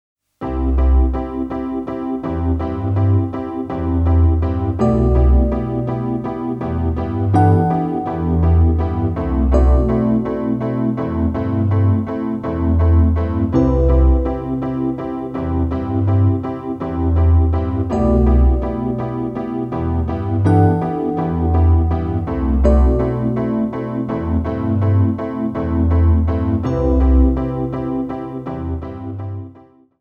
温もりと清涼感を帯びた音色に包み込まれる